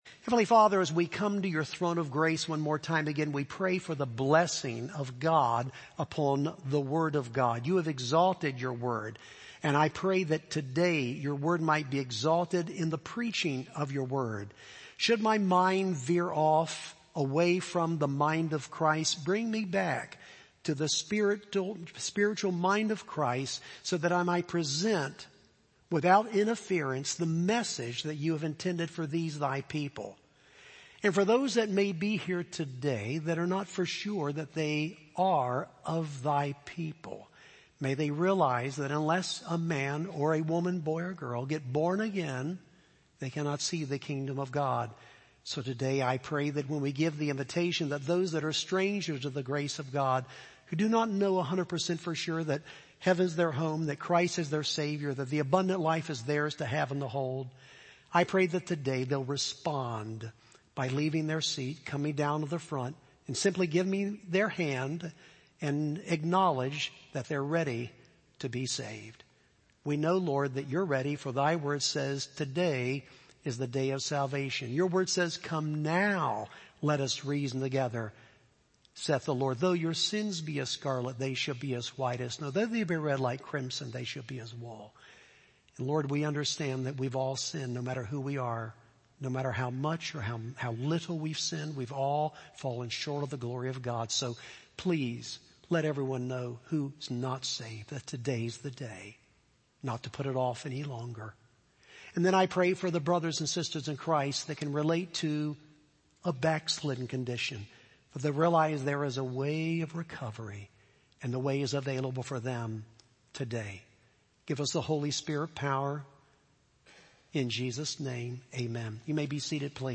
Sermons Podcast - The Backslider and His Recovery - AM | Free Listening on Podbean App